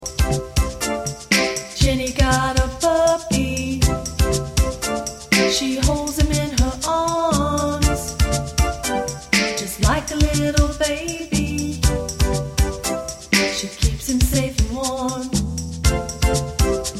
Animal Song Lyrics and Soundclip for Children